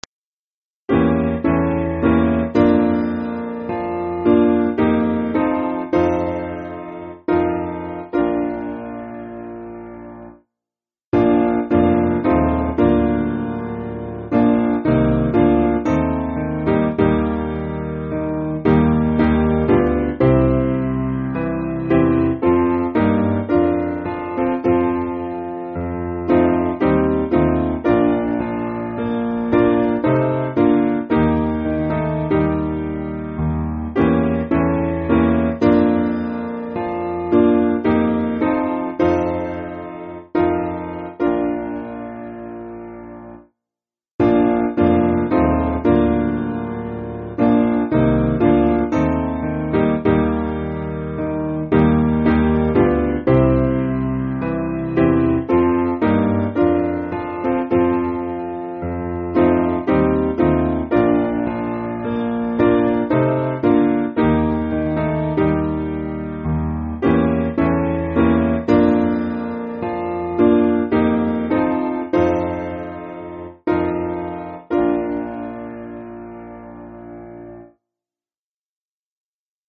Simple Piano
(CM)   2/Bb